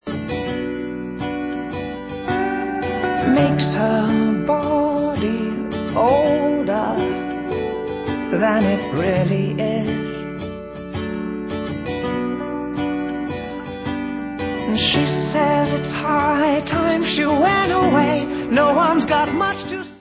Pop